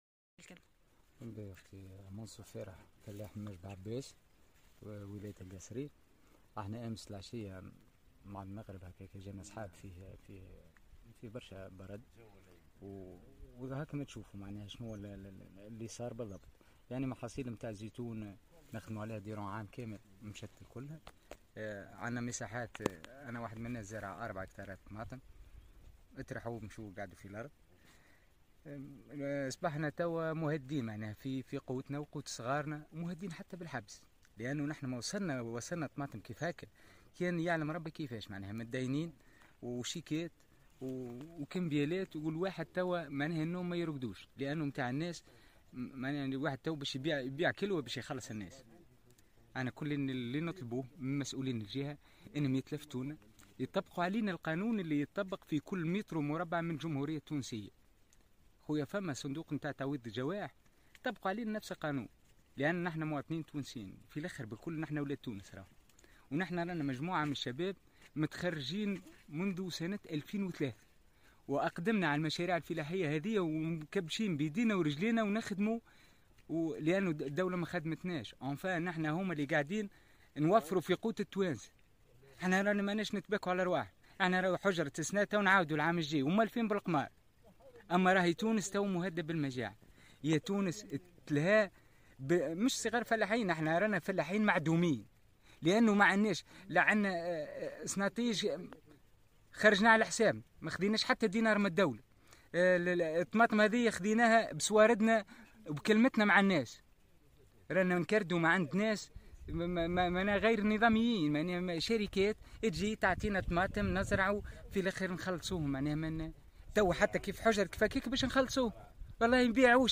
ماجل بلعباس: تضرر هكتارات من الزيتون والفستق والخضروات جراء تساقط البرد (تصريح+صور+فيديو)